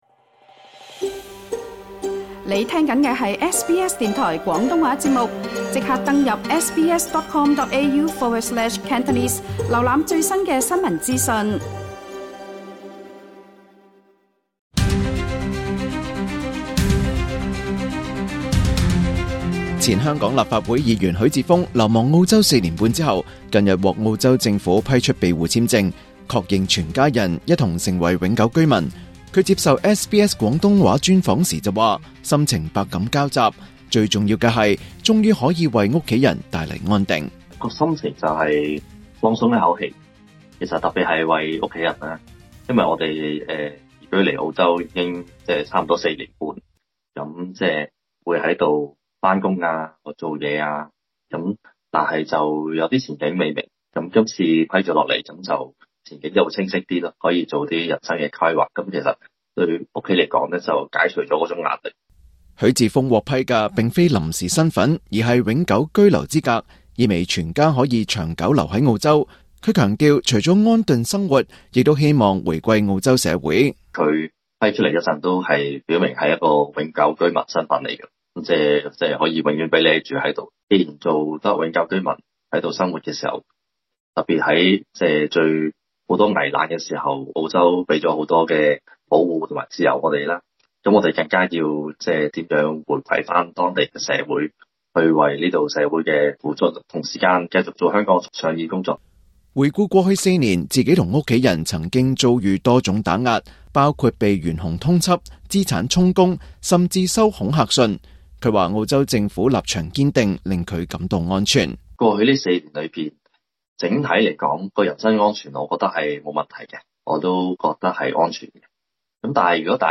流亡澳洲四年半的前香港立法會議員許智峯，近日獲澳洲批出庇護簽證，全家成為永久居民，他接受SBS廣東話專訪時形容是「鬆一口氣」，並強調會繼續為香港發聲。